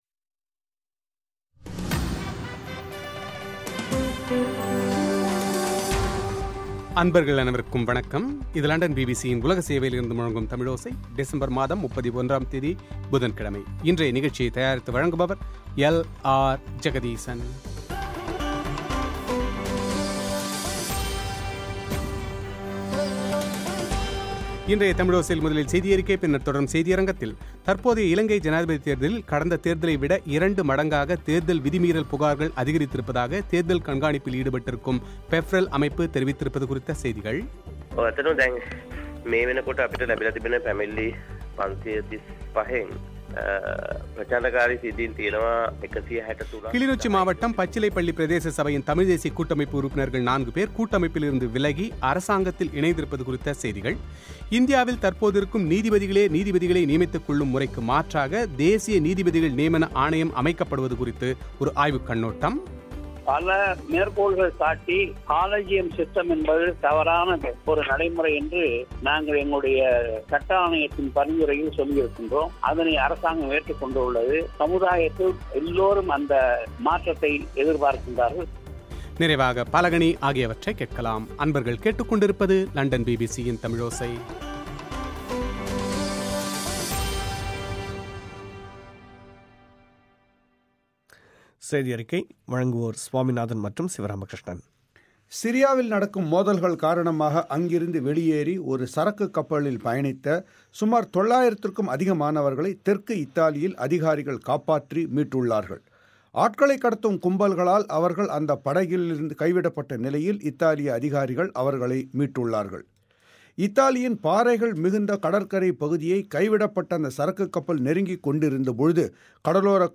மத்திய அரசின் இந்த முடிவு தேவையா என்பது குறித்து இந்திய உச்சநீதிமன்றத்தின் ஓய்வுபெற்ற நீதிபதி ஏ ஆர் லட்சுமணனின் செவ்வி;